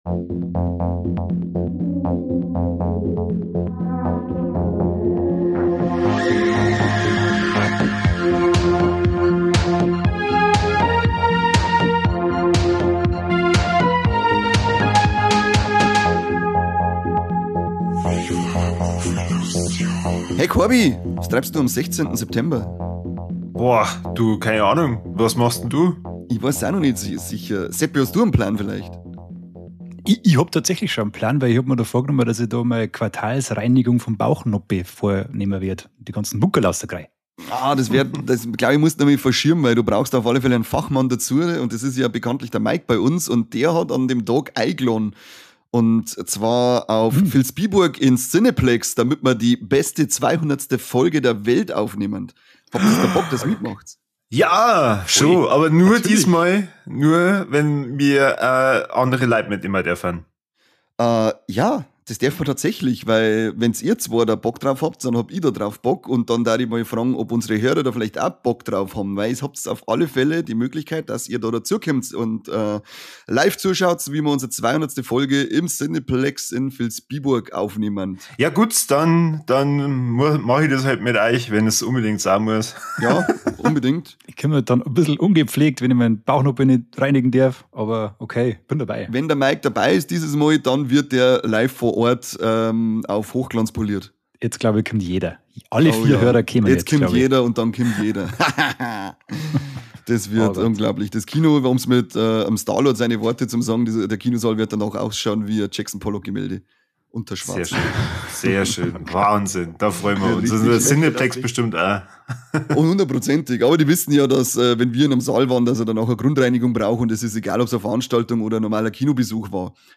Wie bei einer Stammtischrunde gehts in so mancher Sendung mit bayrischem Dialekt zu.